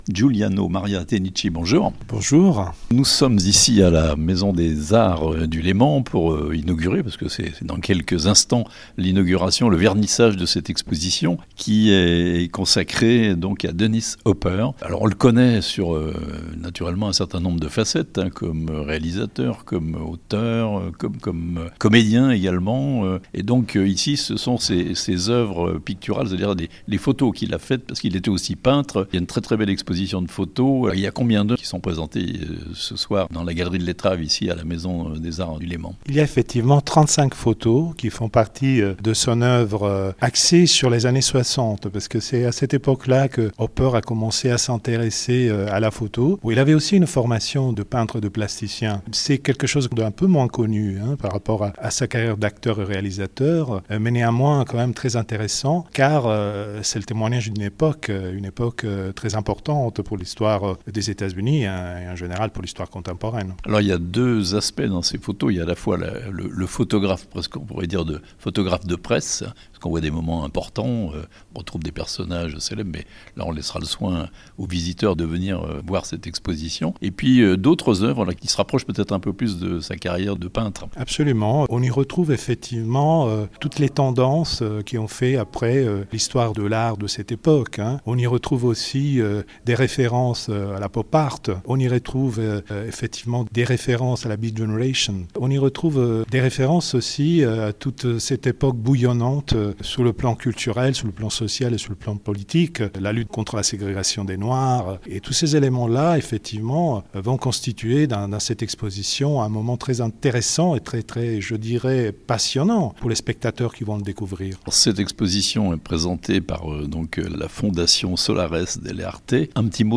Une expo-photo majeure à Thonon : Dennis Hopper "Out of the Sixties" (interviews)